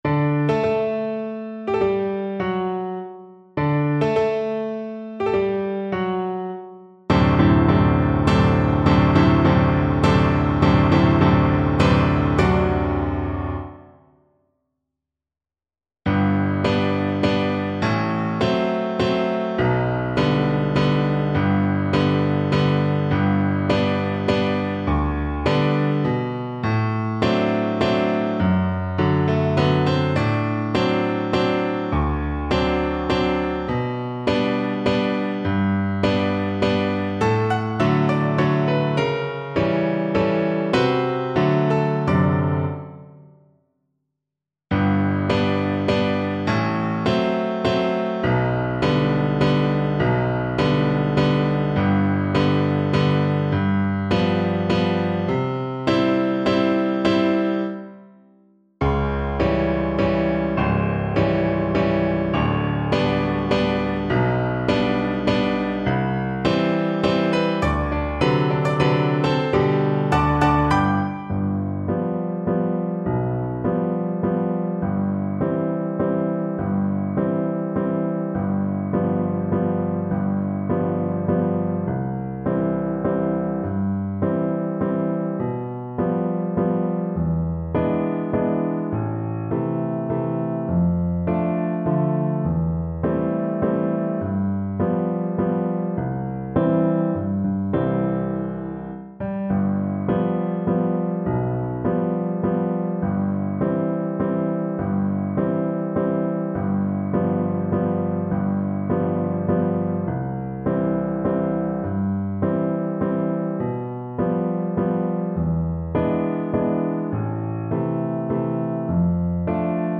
Play (or use space bar on your keyboard) Pause Music Playalong - Piano Accompaniment Playalong Band Accompaniment not yet available transpose reset tempo print settings full screen
Cello
G major (Sounding Pitch) (View more G major Music for Cello )
Allegre brilhante =148
3/4 (View more 3/4 Music)
Classical (View more Classical Cello Music)